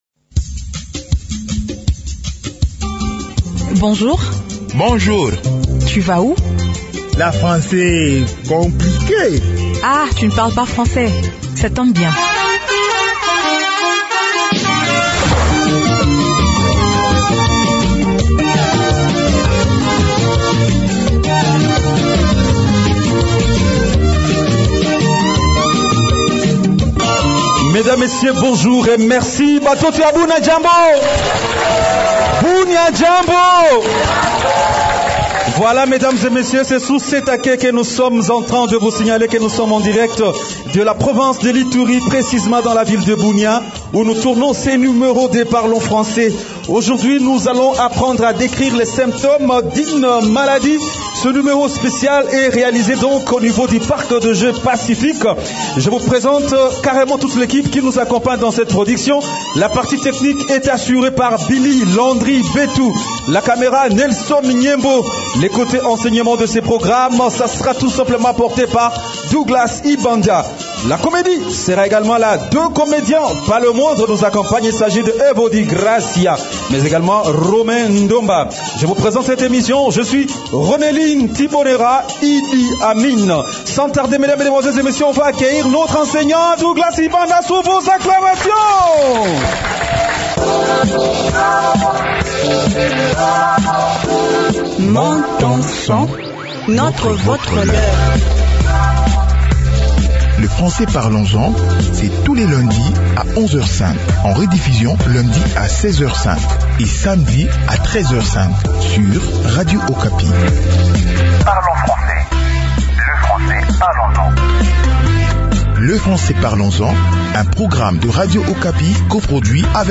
Avec nos auditeurs de Bunia, suivez cette nouvelle leçon sur la manière de décrire les symptômes d’une maladie en français.
Le rythme et le style d’enseignement sont adaptés à votre niveau.